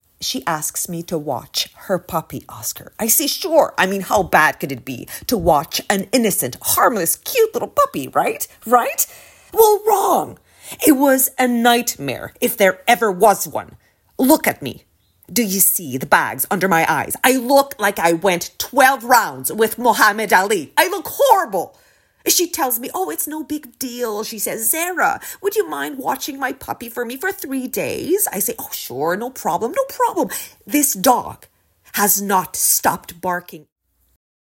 Timbre Grave
Puppy - Stressée (stressed out) - Irritée (annoyed) - Anglais /
Livre audio - Fictif 2022 00:35 939 Ko